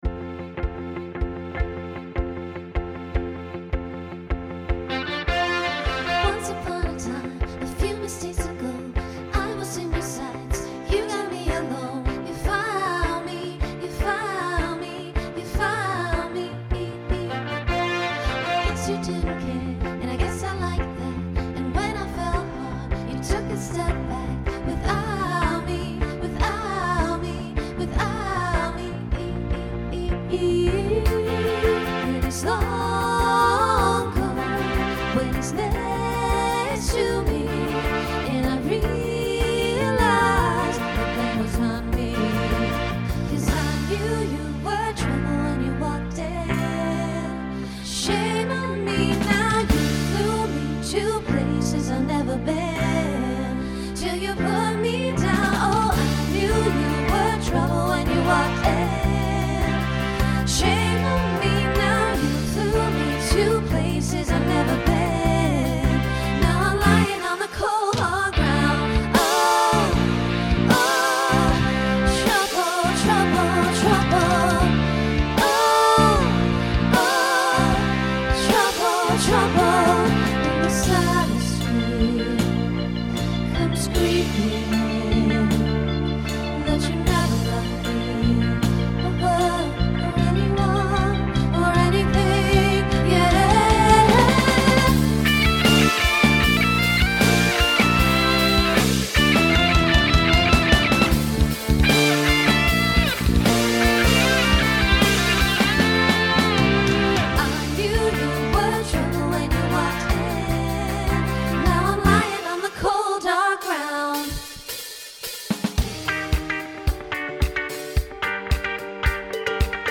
SSA/TTB
Voicing Mixed
Genre Pop/Dance , Rock